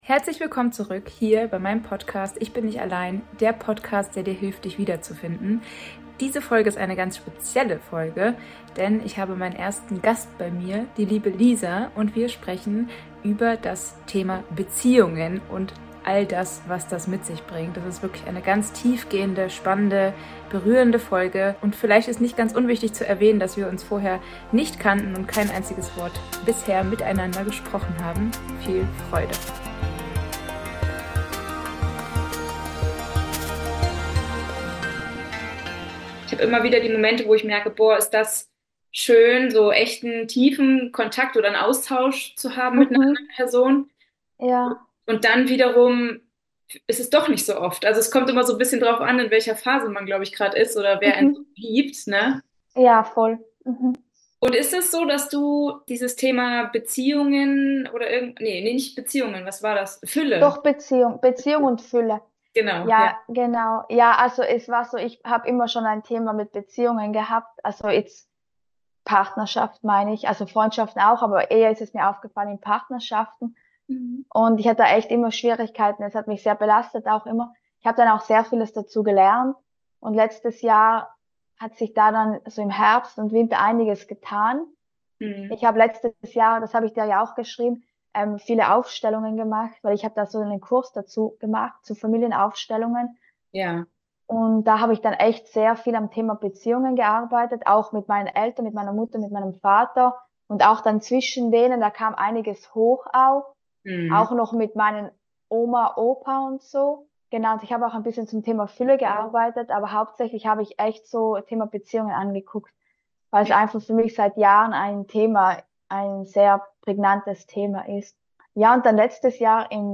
Herausgekommen ist ein Gespräch zweier Menschen, die noch nie miteinander zu tun hatten – und das dennoch von Anfang an so richtig in die Tiefe geht. Wir sprechen vor allem über Beziehungen, ein zentrales Thema für unser Leben – wie kann es auch anders sein bei zwei Menschen mit 4er-Linie?